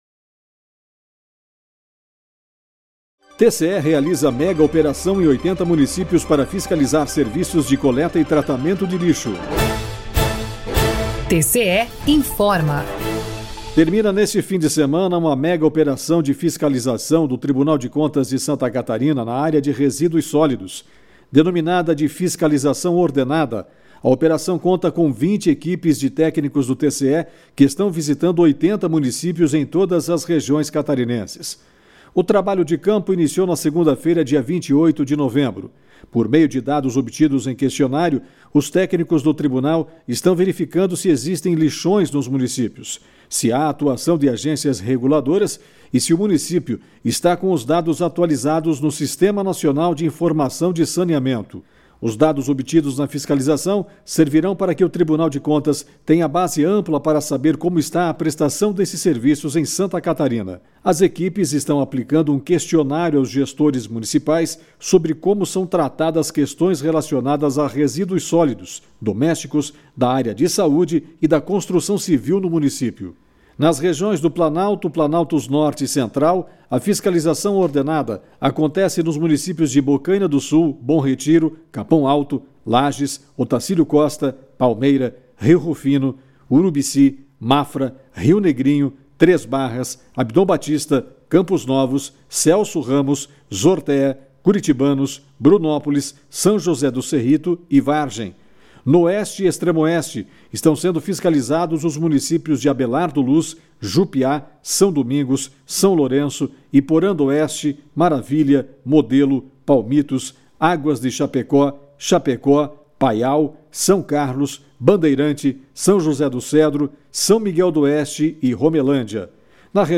VINHETA TCE INFORMA